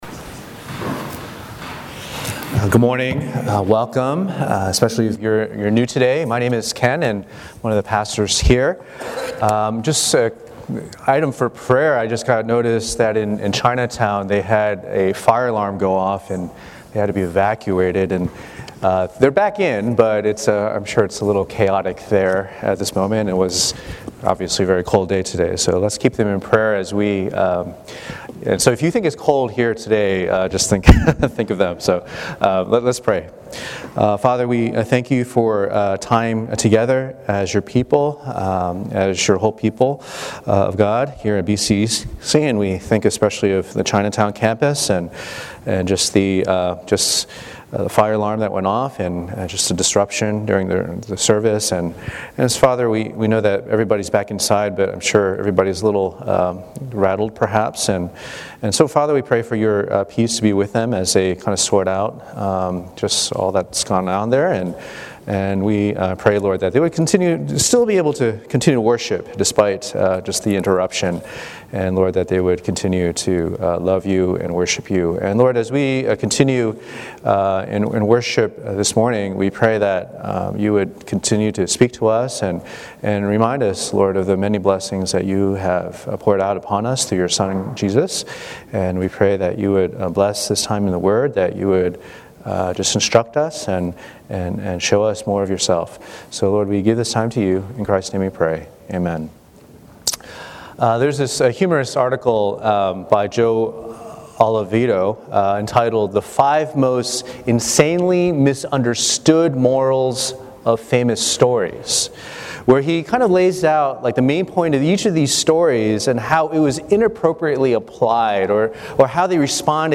Tag: Recent Sermons - Page 86 of 178 | Boston Chinese Evangelical Church